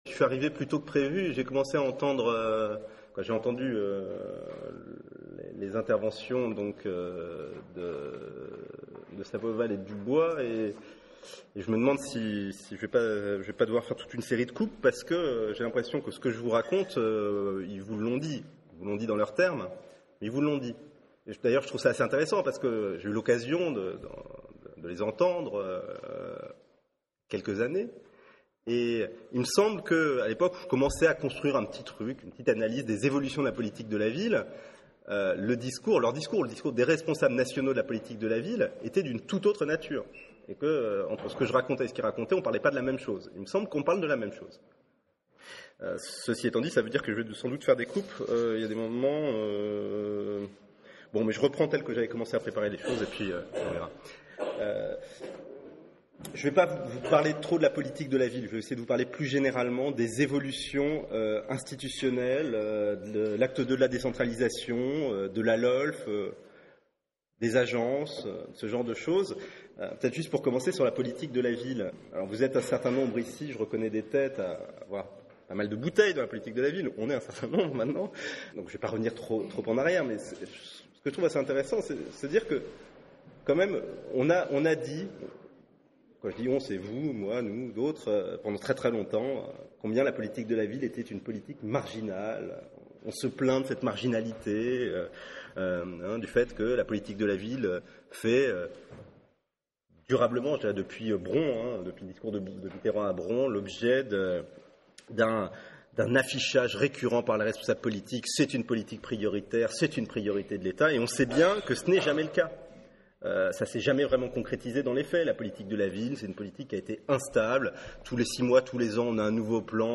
Une analyse éclairante à retrouver dans l’enregistrement de son intervention (ci-dessous) et dans l’article d’ESPRIT.